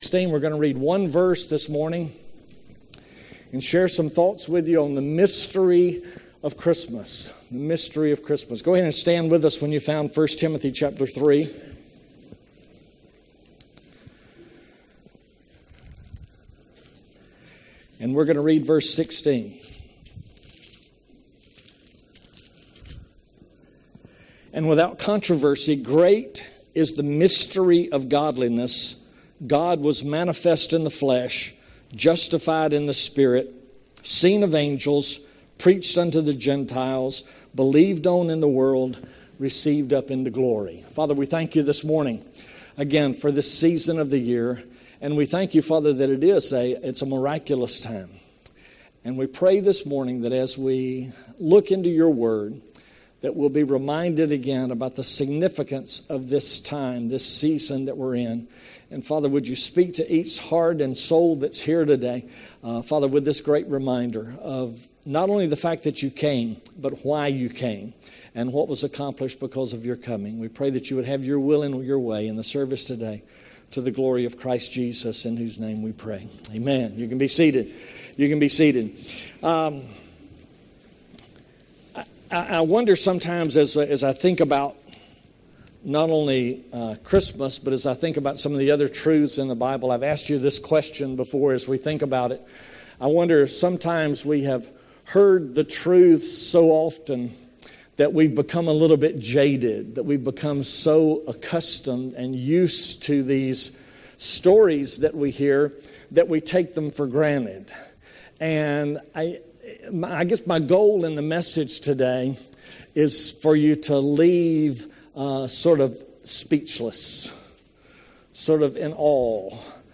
Purpose Baptist Church Sermon Audios